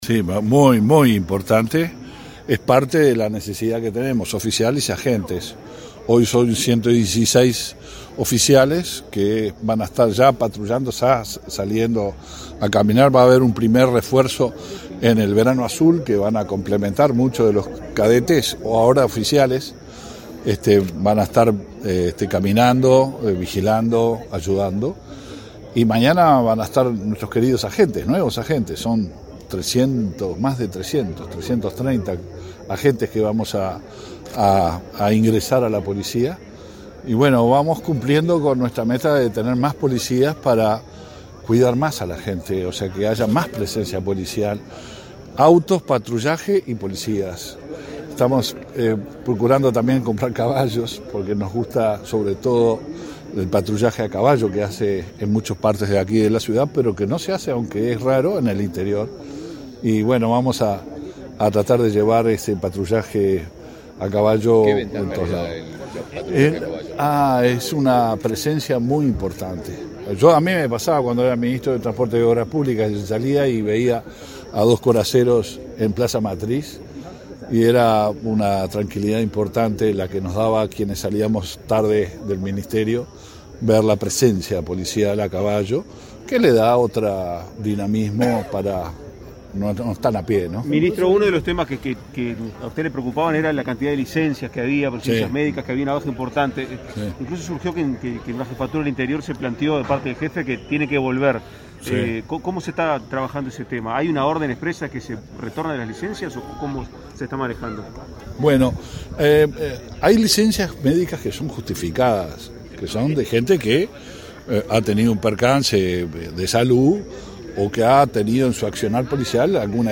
Declaraciones a la prensa del ministro del Interior, Luis Alberto Heber